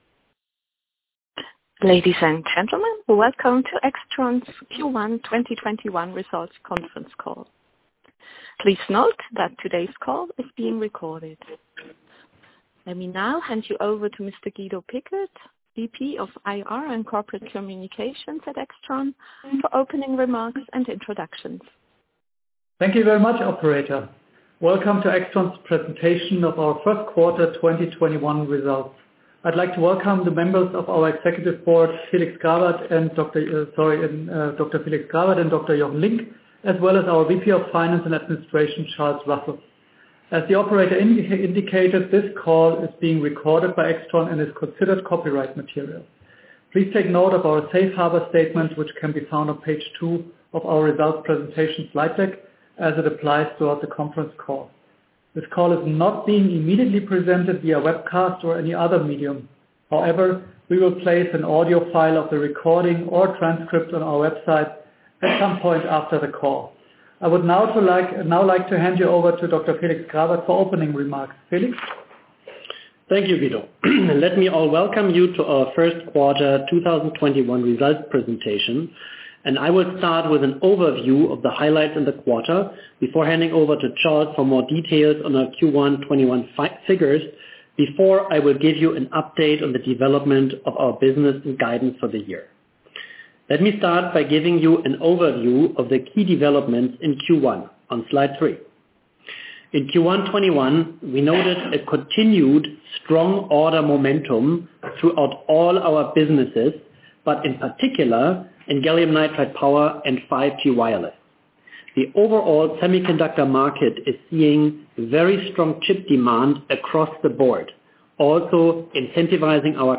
Q1/2021 Results Analyst Conference Call